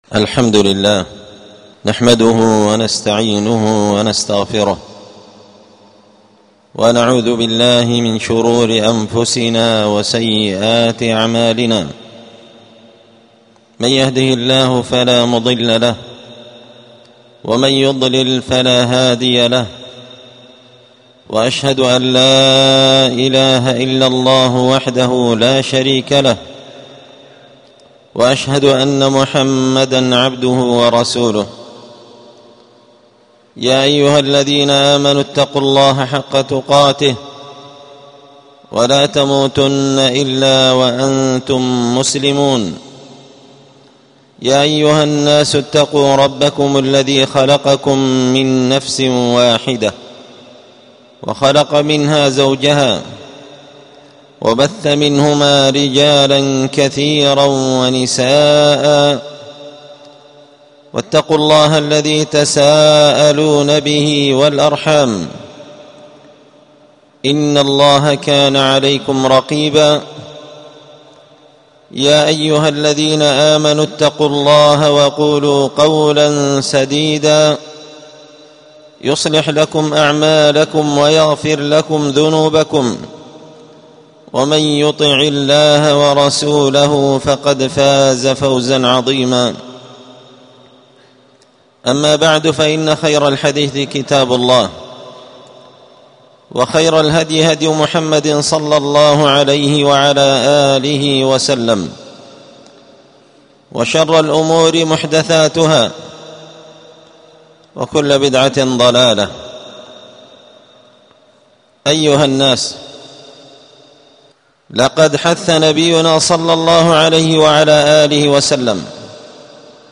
ألقيت هذه الخطبة بدار الحديث السلفية بمسجد الفرقان